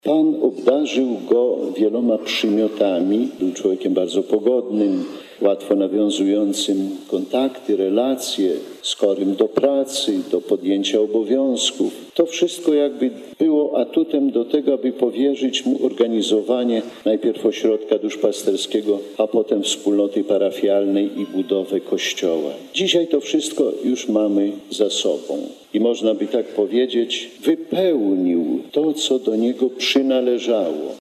Choć odszedł w wieku 57 lat to jednak udało mu się wiele rzeczy w życiu zrealizować – zwrócił uwagę bp Kamiński.